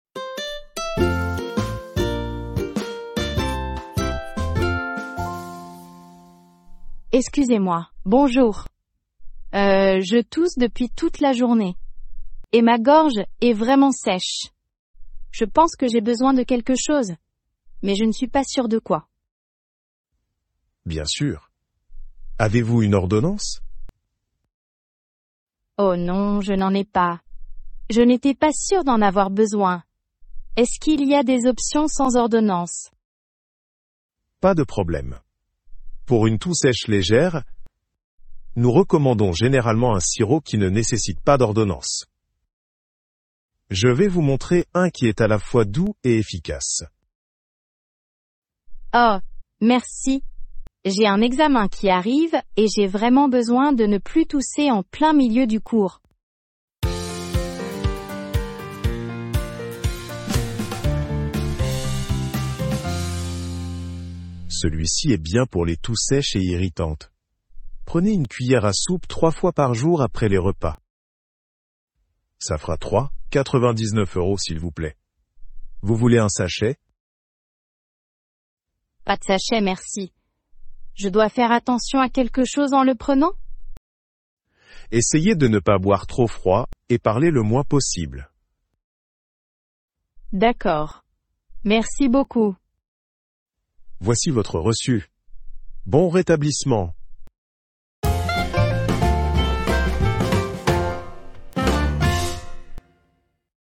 Dialogue à la pharmacie : la toux – Niveau A2